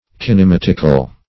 Search Result for " kinematical" : The Collaborative International Dictionary of English v.0.48: Kinematic \Kin`e*mat"ic\, Kinematical \Kin`e*mat"ic*al\, a. Of or pertaining to kinematics.